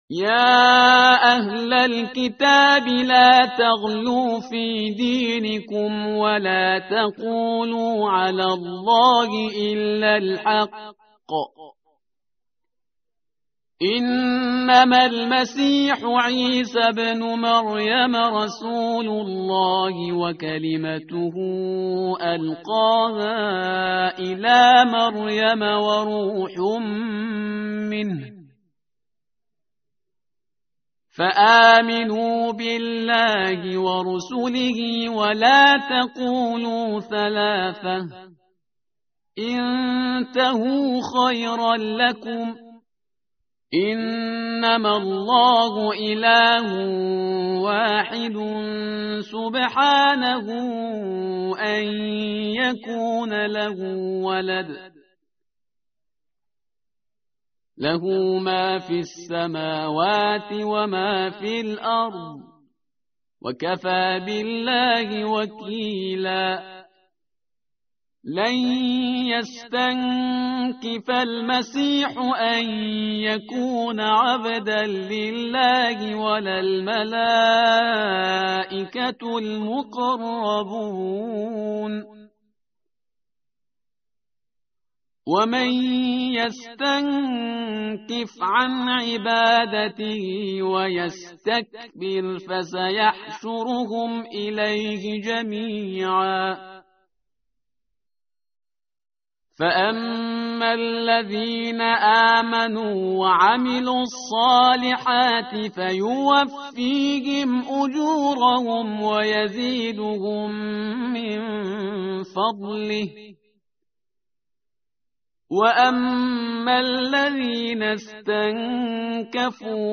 tartil_parhizgar_page_105.mp3